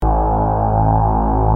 Sequencial Circuits - Prophet 600 48